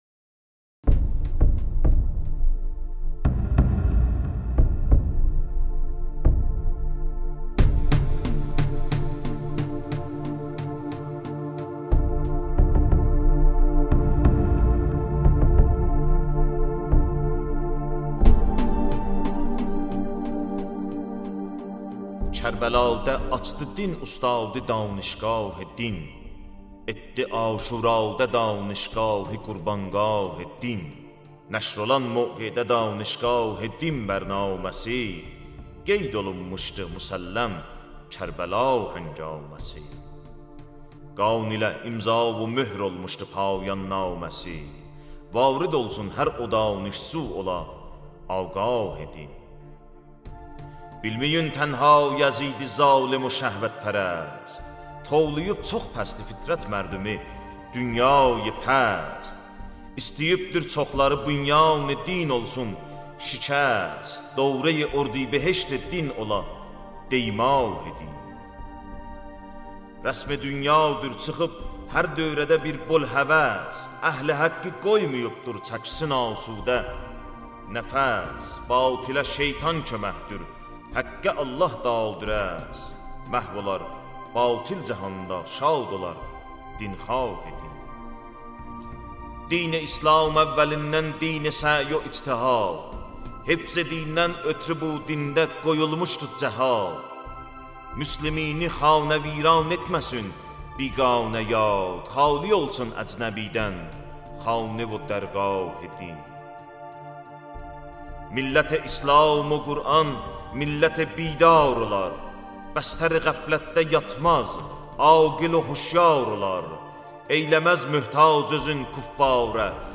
خواننده : موسیقی اسلامی
دسته : موسیقی ملل